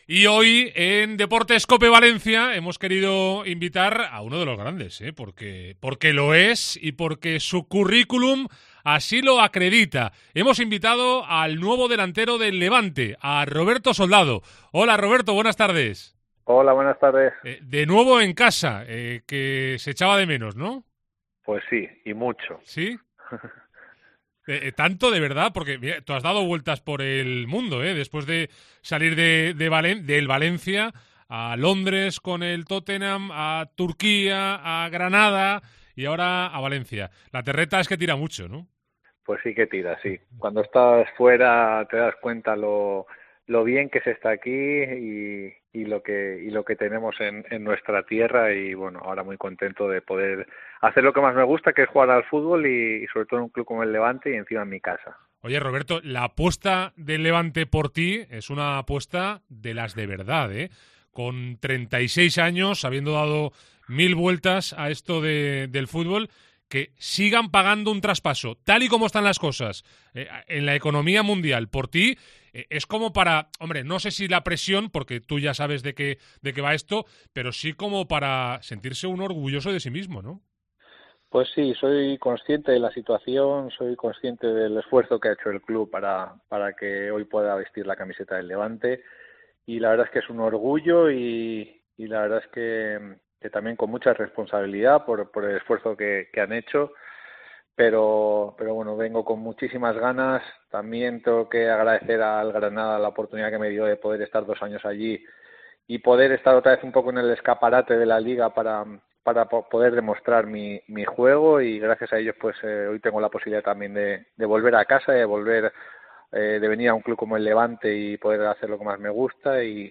ENTREVISTA COPE
AUDIO. Entrevista a Soldado en Deportes COPE Valencia